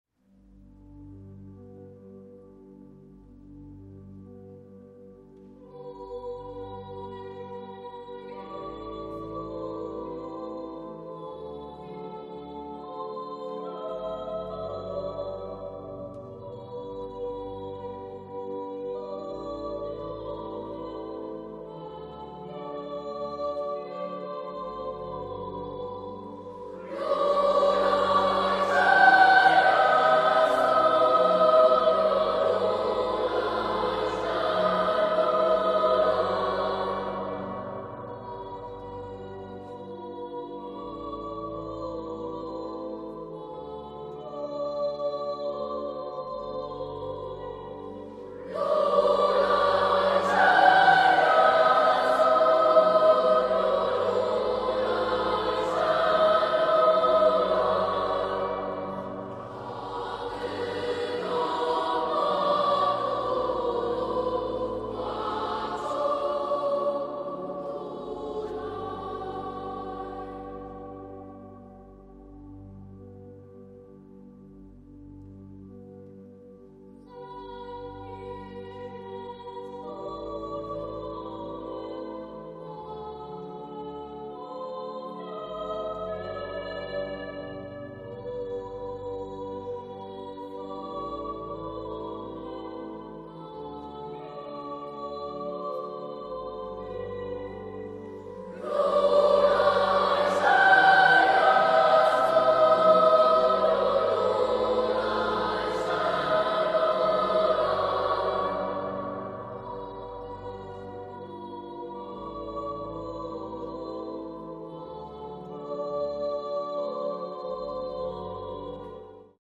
kolêda - wykonuje chór ch³opiêcy